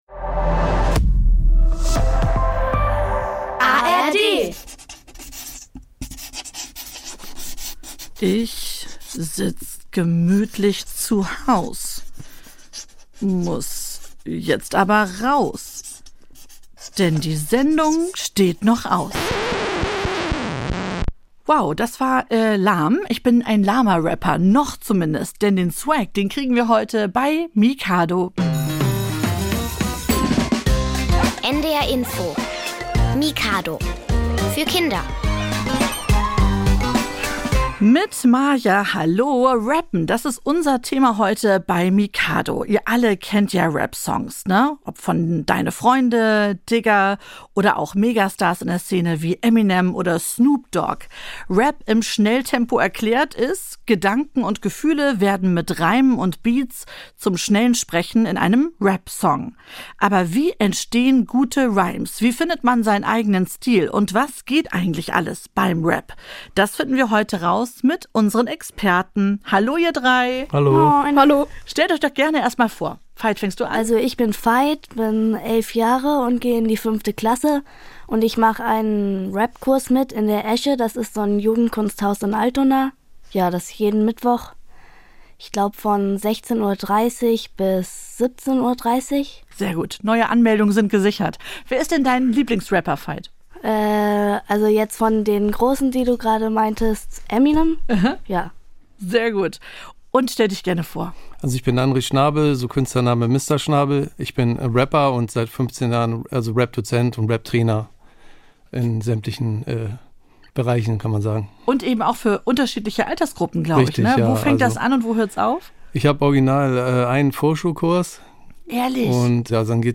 Aber wie kann man damit anfangen und was muss man dabei alles beachten? Im Mikado-Studio verraten drei Rapper, wie aus Gedanken ganze Rapsongs werden - und gerappt wird natürlich auch!